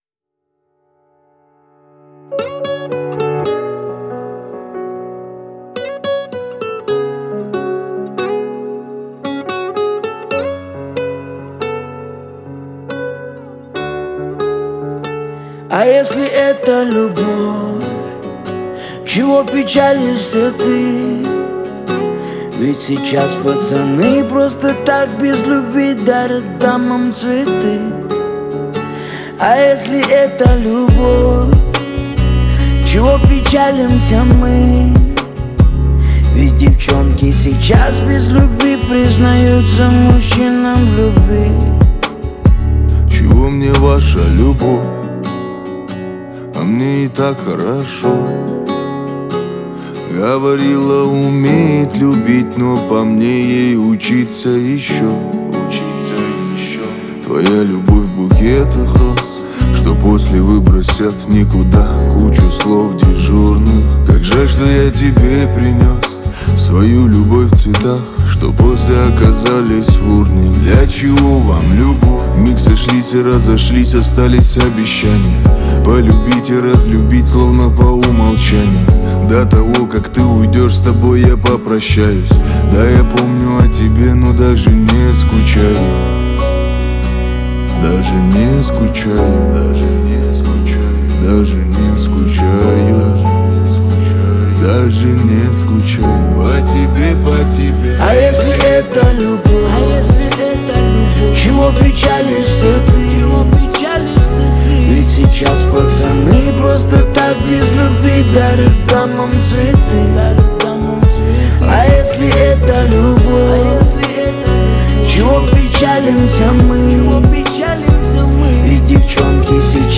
песня в жанре современного рэп и R&B